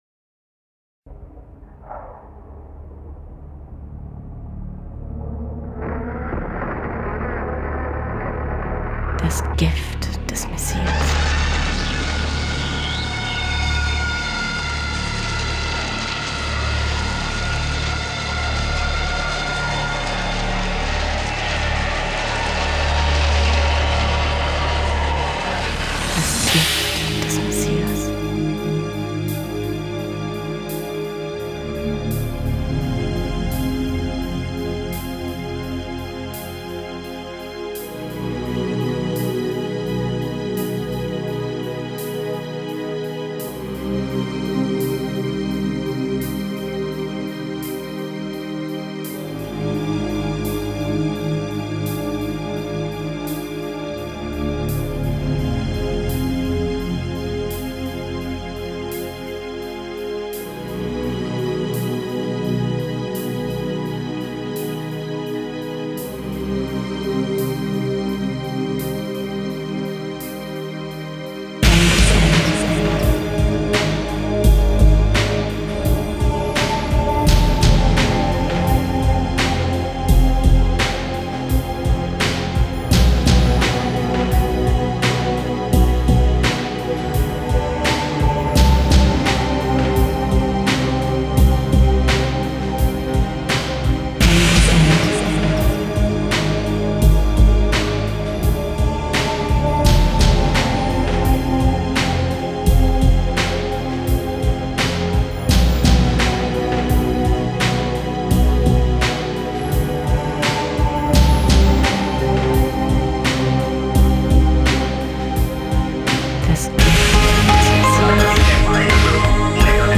*фоновая композиция – Diary Of Dreams «(ver)Gift(et)?»